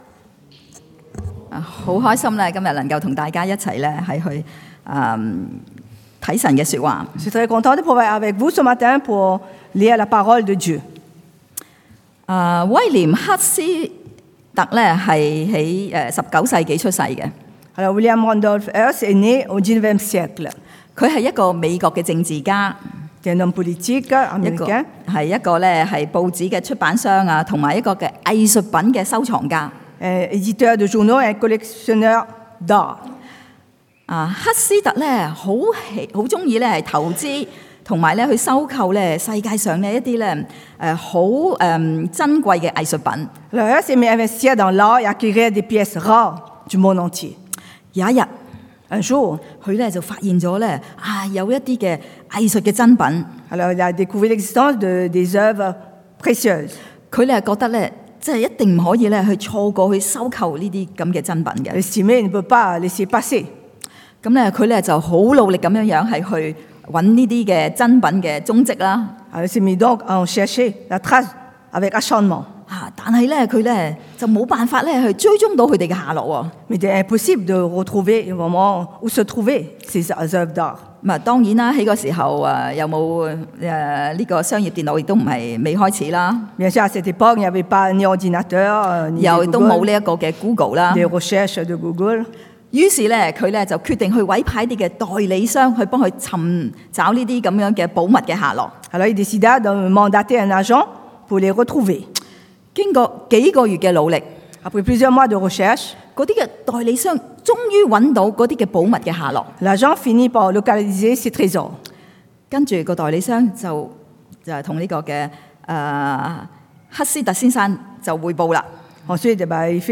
Connaître la puissance de Dieu 認識神的大能 – Culte du dimanche